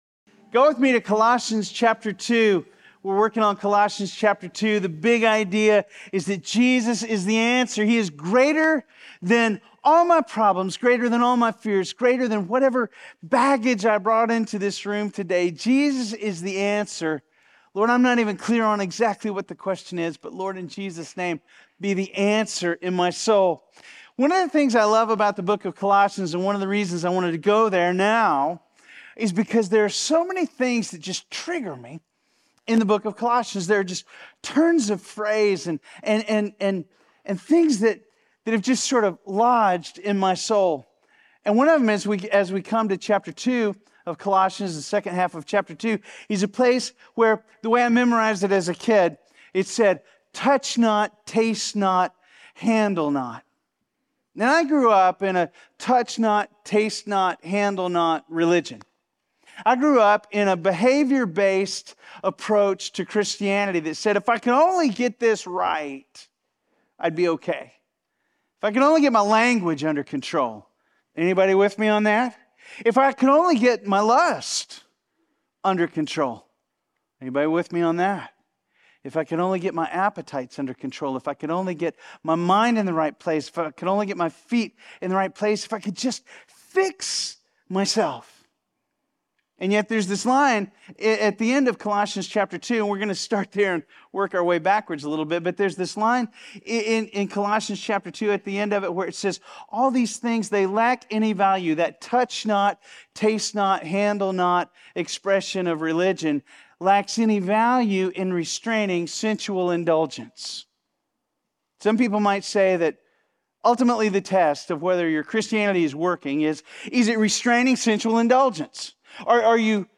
Sermons | FBC Platte City